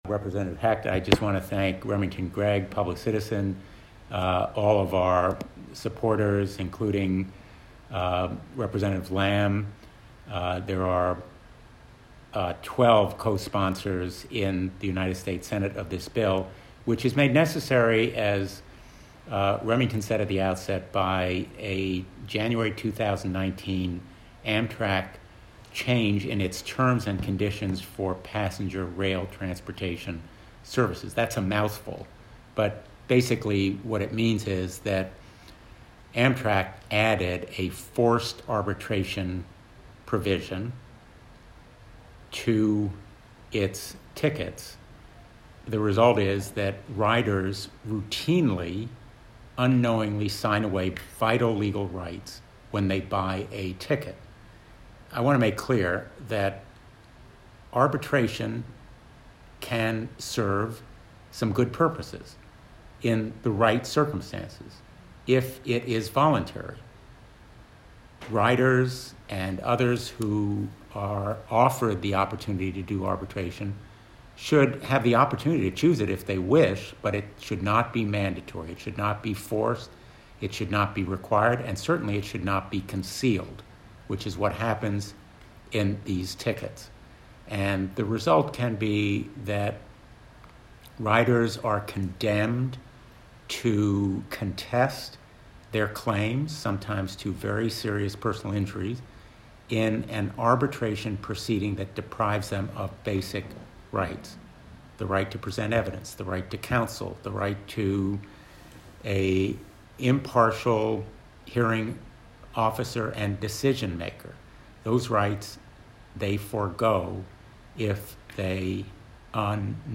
Blumenthal’s remarks from today’s press call are